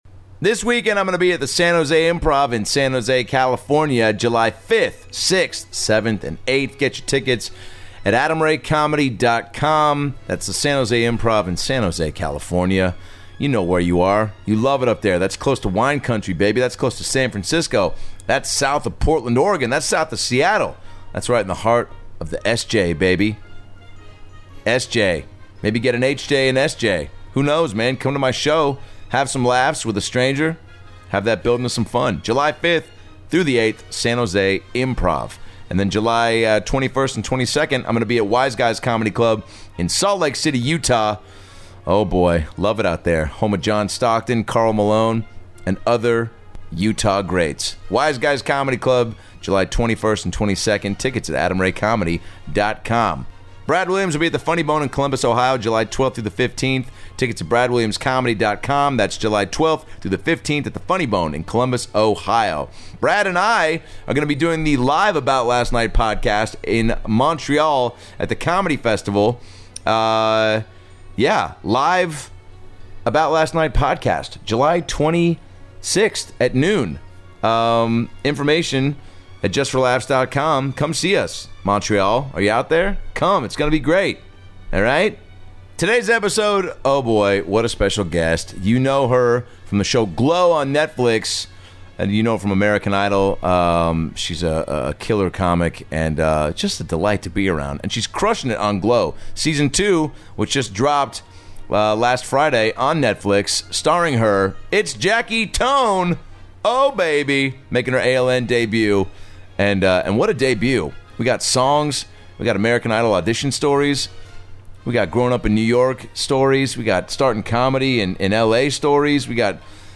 Jackie Tohn stops by to talk season 2 of GLOW, growing up in NYC, her American Idol audition & more! Non-stop laughs in this episode, complete with a sing-a-long